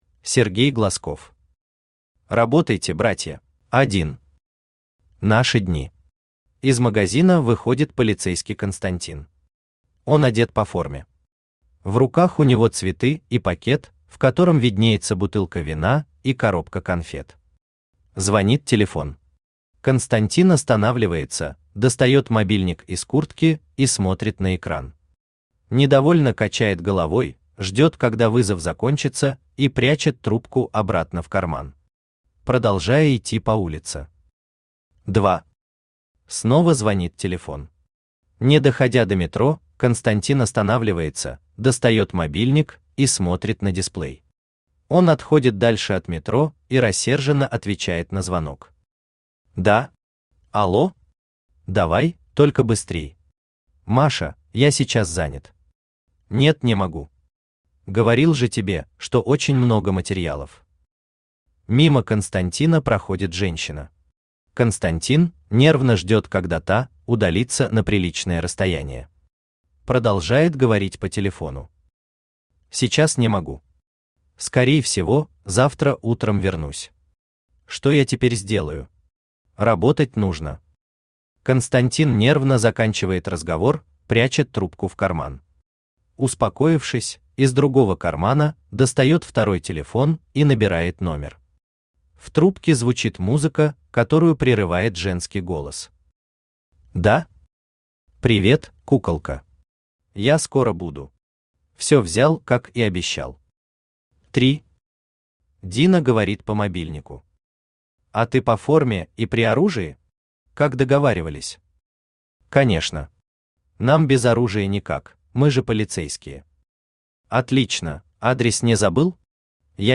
Аудиокнига Работайте, братья!
Автор Сергей Алексеевич Глазков Читает аудиокнигу Авточтец ЛитРес.